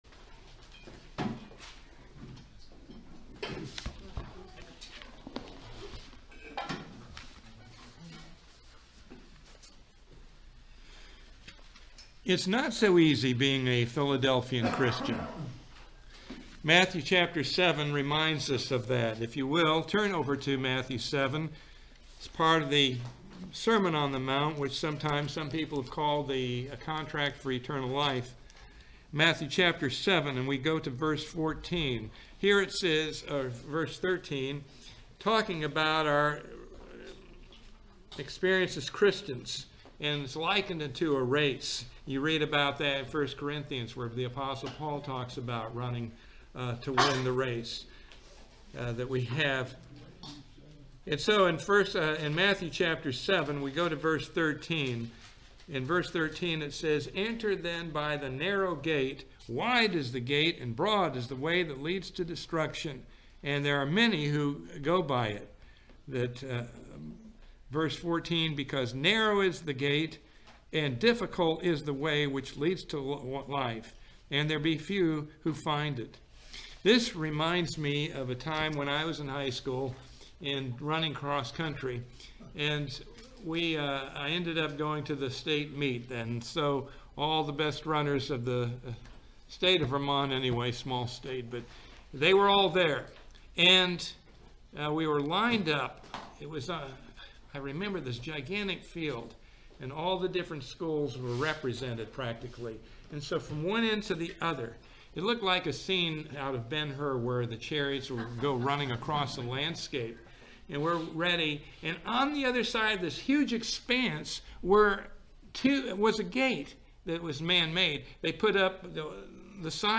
Sermons
Given in Ocala, FL